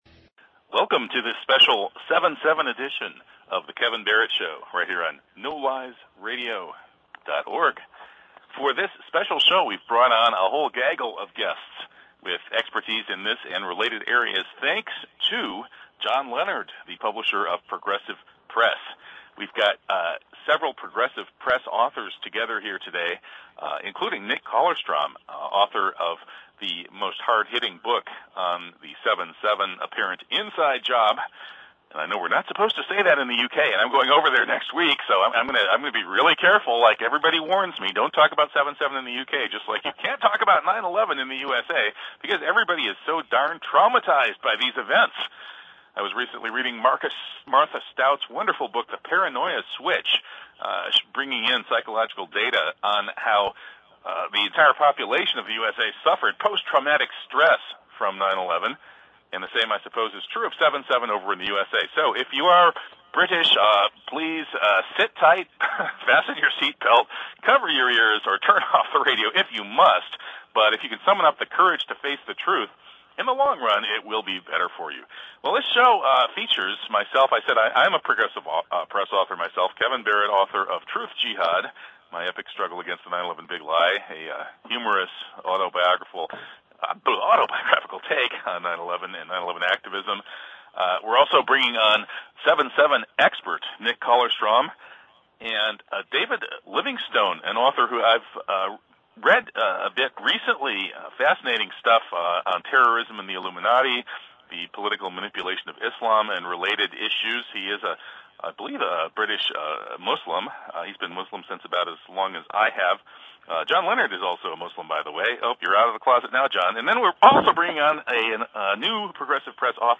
The radio broadcast transcribed below aired on NoLiesRadio July 7, 2010.